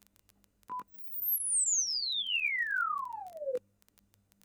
Acoustics Test / PallasPlus Chirp Test